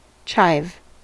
chive: Wikimedia Commons US English Pronunciations
En-us-chive.WAV